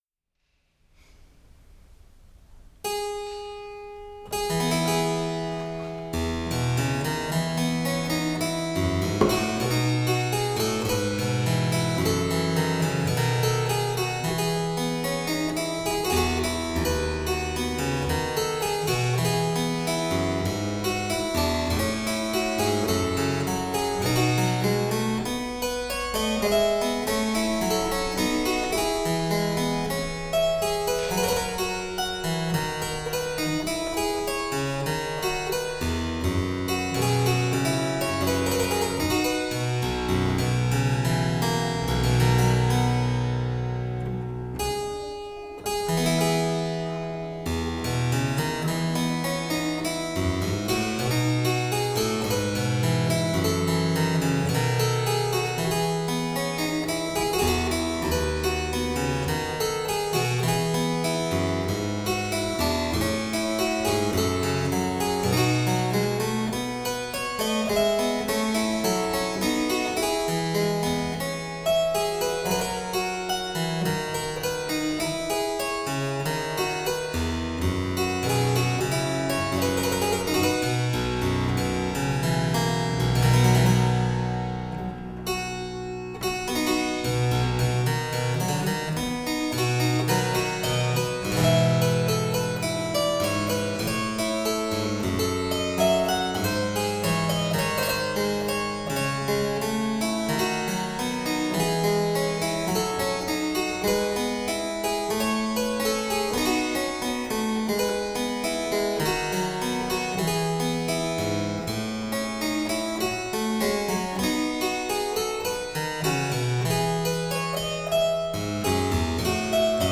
This is a copy of the only known single-manual harpsichord of Blanchet (Paris 1736).
Compass: GG-e”’, 2 x 8′, strung in brass and iron, 415/440.
The audio file is a part of the inauguration-concert what I played.
1. Allemande – short 8′
2. Courante – long 8′
3. Sarabande – 2 x 8′
4. Double – long 8′
5. Gigue – 2 x 8′